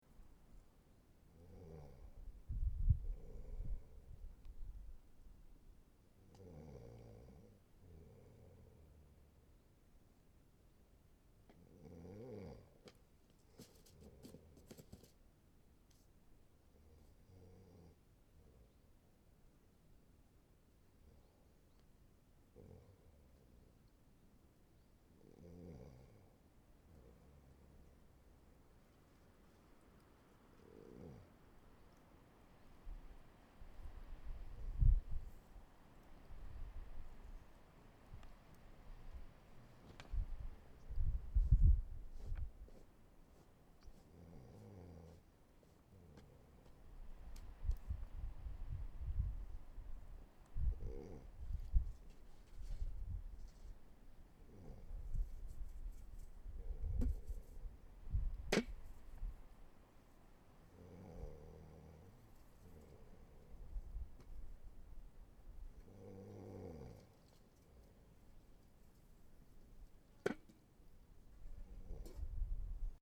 desert3_ronron.mp3